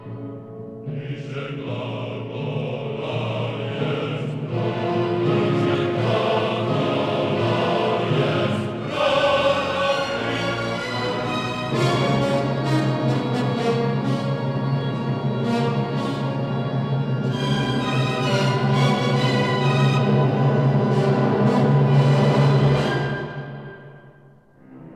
soprano
alto
tenor
bass
organ
Stereo recording made in Dvořák Hall, Prague 22- 24 May 1961